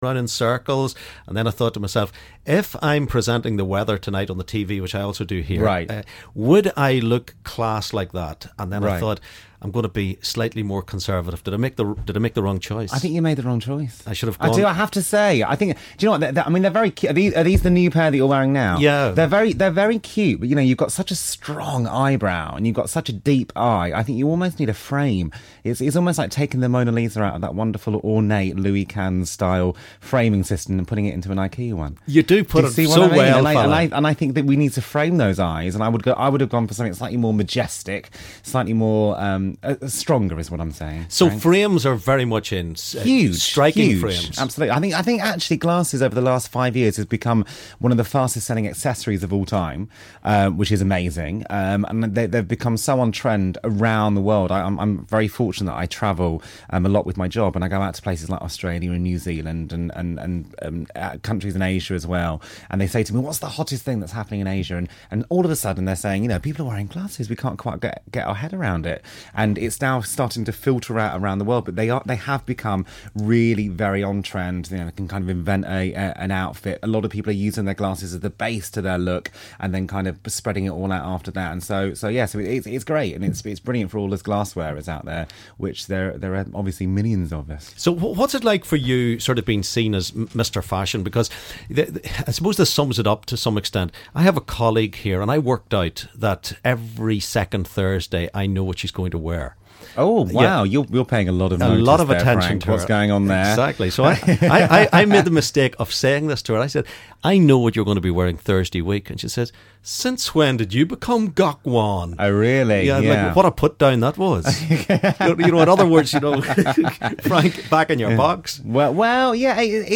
Interviews Gok Wan